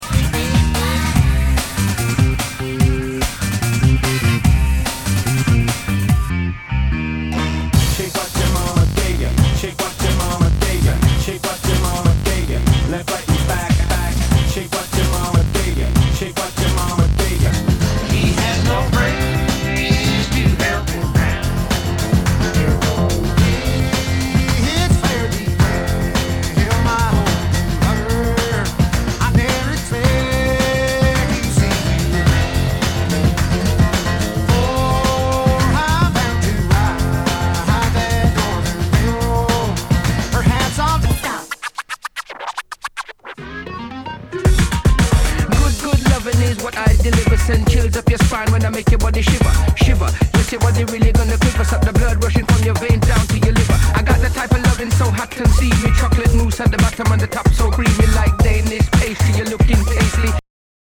Nu- Jazz/BREAK BEATS
ナイス！ファンキー・ブレイクビーツ！